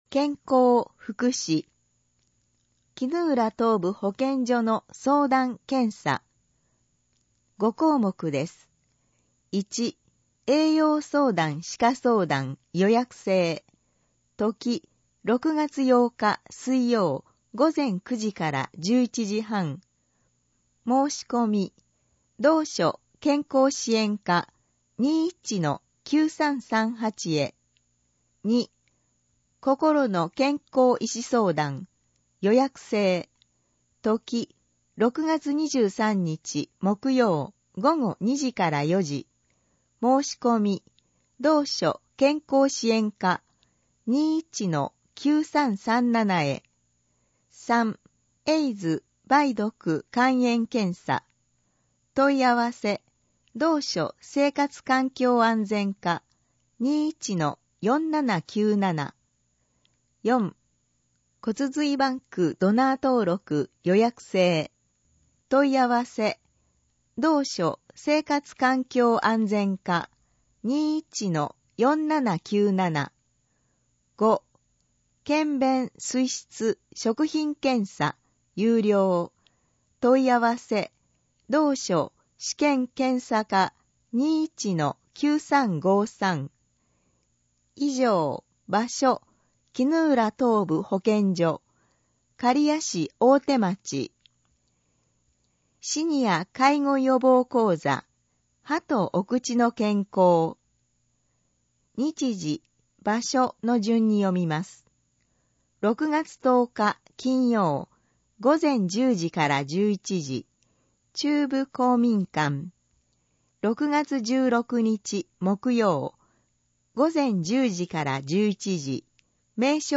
以上の音声データは、「音訳ボランティア安城ひびきの会」の協力で作成しています